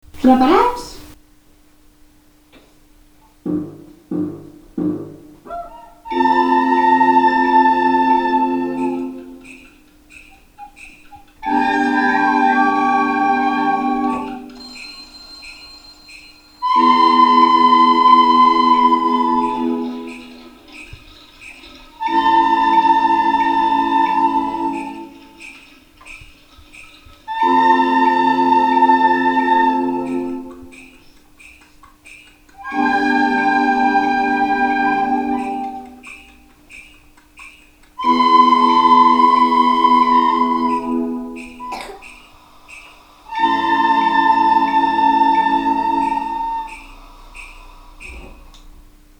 Els nens i nenes de la classe de les Fades i Follets ja han començat a tocar la flauta i sona així de bé!